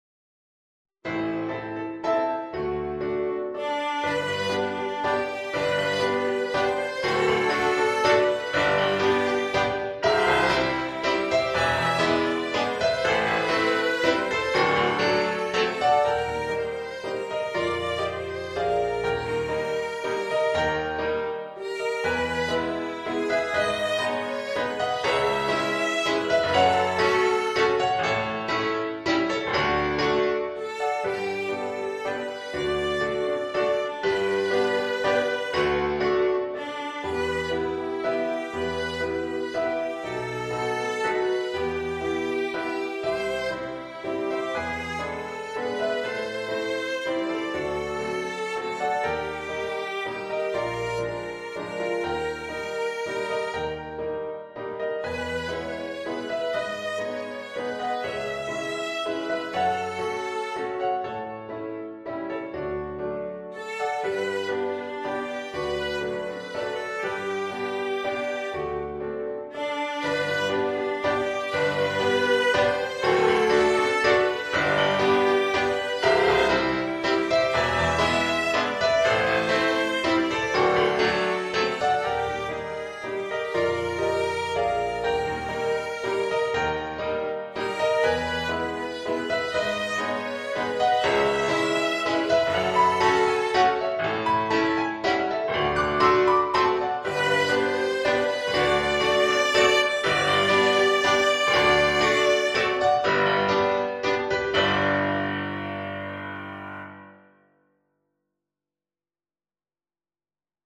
Vínarvals við kvæði eftir Þorstein Gylfason